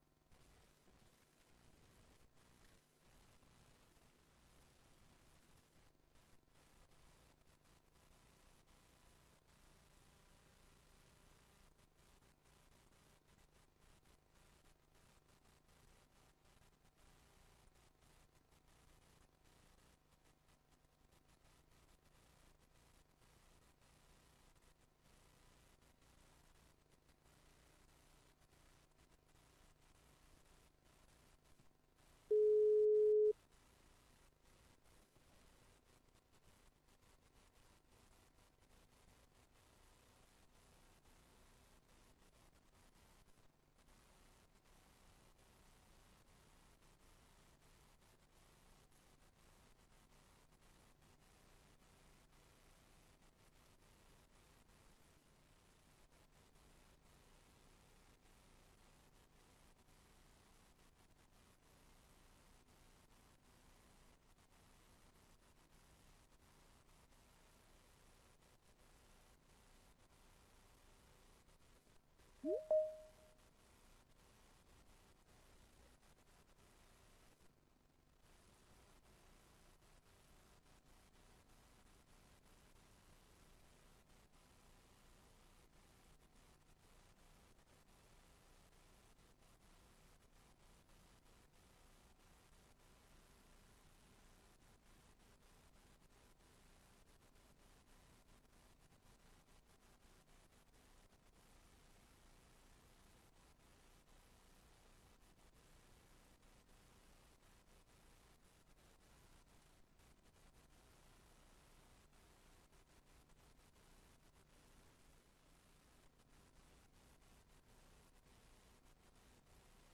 Raadsbijeenkomst 05 maart 2025 19:30:00, Gemeente Tynaarlo
Locatie: Raadszaal